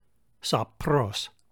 σαπρός / sapros